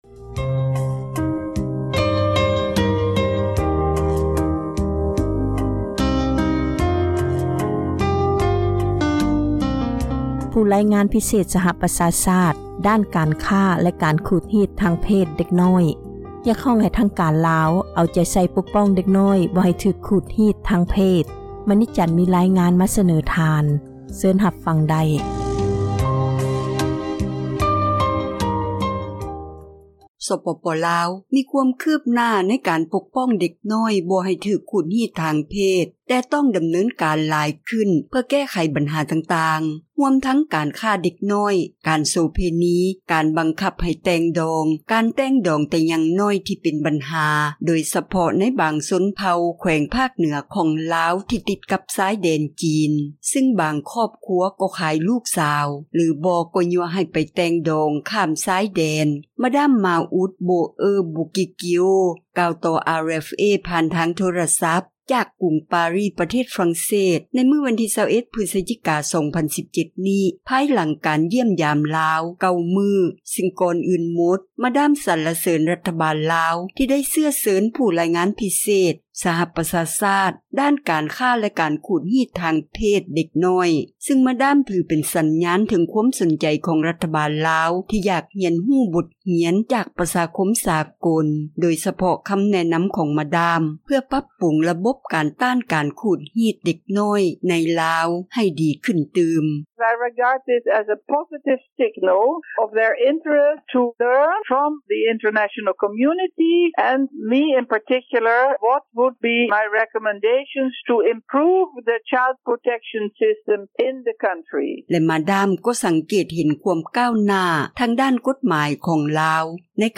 ມາດາມ Maud Bauer-Buquicchio ກ່າວຕໍ່ RFA ຜ່ານທາງໂທຣະສັບ ຈາກກຸງປາຣີ ປະເທດຝຣັ່ງເສດ ໃນມື້ວັນທີ 21 ພຶສຈິກາ 2017 ນີ້ ພາຍຫລັງການຢ້ຽມຢາມລາວ 9 ມື້ ຊຶ່ງກ່ອນອື່ນໝົດ ມາດາມສັລເສີນຣັຖບານລາວ ທີ່ໄດ້ເຊື້ອເຊີນ ຜູ້ຣາຍງານພິເສດ ສະຫະປະຊາຊາດ ດ້ານການຄ້າ ແລະການຂູດຮີດທາງເພດເດັກນ້ອຍ ຊຶ່ງ ມາດາມ ຖືເປັນສັນຍານ ເຖິງຄວາມສົນໃຈ ຂອງຣັຖບານລາວ ທີ່ຢາກຮຽນຮູ້ບົດຮຽນ ຈາກປະຊາຄົມ ສາກົລ ໂດຍສະເພາະ ຄໍາແນະນໍາ ຂອງມາດາມ ເພື່ອປັບປຸງ ຣະບົບການຕ້ານ ການຂູດຮີດ ເດັກນ້ອຍໃນລາວ ໃຫ້ດີຂຶ້ນ ຕື່ມ. ແລະ ມາດາມ ກໍສັງເກດເຫັນ ຄວາມກ້າວໜ້າ ທາງດ້ານກົດໝາຍຂອງລາວ ໃນການແກ້ໄຂບັນຫາ ການຄ້າມະນຸດ ຮວມທັງການຄ້າ ເດັກນ້ອຍ.